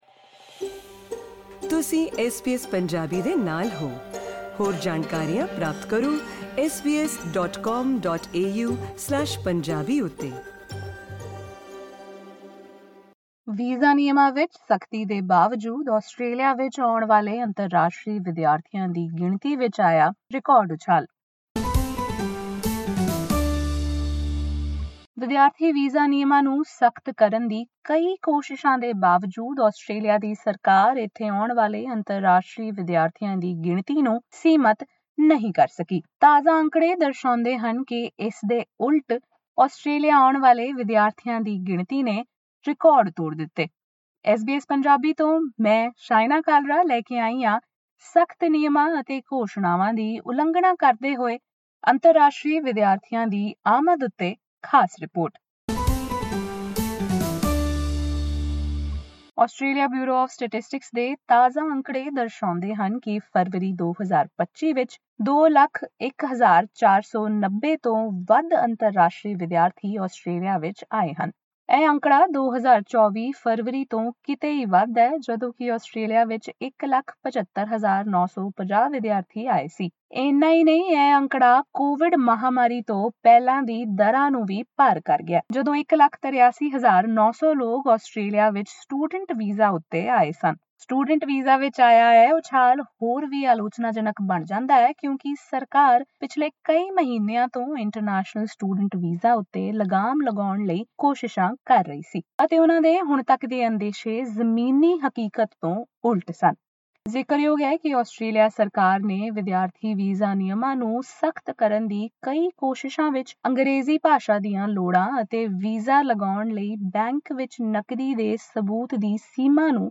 ਅਜਿਹੇ ਵਿੱਚ ਇਨ੍ਹਾਂ ਨਵੇਂ ਅੰਕੜਿਆਂ ਦਾ ਕੀ ਮਤਲਬ ਹੈ ਅਤੇ ਆਉਣ ਵਾਲੇ ਦਿਨਾਂ ਵਿੱਚ ਇਸਦਾ ਕੀ ਅਸਰ ਹੋ ਸਕਦਾ ਹੈ ਜਾਨਣ ਲਈ ਸੁਣੋ ਐਸ ਬੀ ਐਸ ਪੰਜਾਬੀ ਦੀ ਇਹ ਰਿਪੋਰਟ: LISTEN TO ਵੀਜ਼ਾ ਨਿਯਮਾਂ ਵਿੱਚ ਸਖਤੀ ਦੇ ਬਾਵਜੂਦ, ਆਸਟ੍ਰੇਲੀਆ ਵਿੱਚ ਆਉਣ ਵਾਲੇ ਅੰਤਰਰਾਸ਼ਟਰੀ ਵਿਦਿਆਰਥੀਆਂ ਦੀ ਗਿਣਤੀ ਵਿੱਚ ਰਿਕਾਰਡ ਉਛਾਲ SBS Punjabi 04:20 Panjabi ਸਾਡੇ ਸਾਰੇ ਪੌਡਕਾਸਟ ਇਸ ਲਿੰਕ ਰਾਹੀਂ ਸੁਣੇ ਜਾ ਸਕਦੇ ਹਨ।